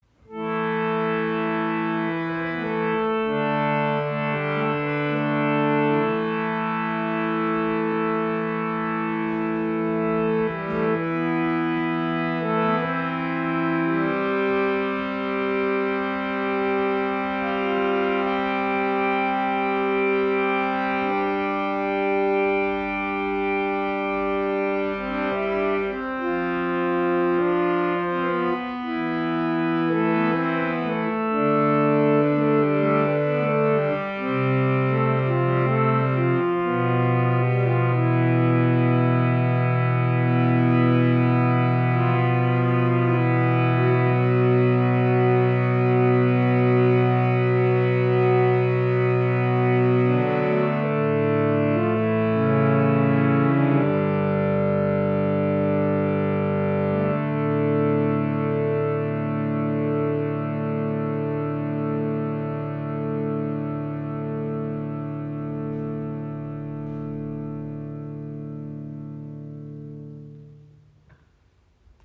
Harmonium | Bhava Studio | Limited Edition Mahogany
Das Bhava Limited Edition Harmonium kombiniert schicke Designelemente mit einer Zedernholzkonstruktion und hat einen warmen Klang und ein unverwechselbares Aussehen.
Wenn es um einen unglaublich langen, kompromissloses Sustain, sanftes Spiel und reiche, dynamische Klangvielfalt geht, ist das Bhava Studio unübertroffen.
Stimmung: Concert Pitch / 440 Hz
Warme Tonalität: Resonanz durch ein massives Zedernholzgehäuse